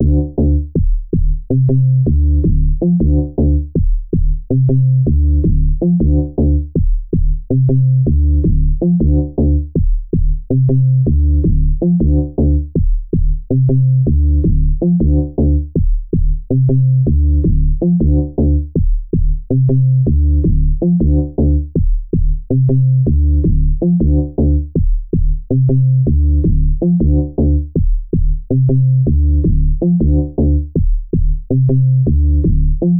ご用意したのは、ステレオの一般的なループ音源と、POシリーズに同期をして楽しめるクリック入りのシンクモードのループ音源です。
ディープ・ベース・シンセ
BPM 80（HIP-HOPモード）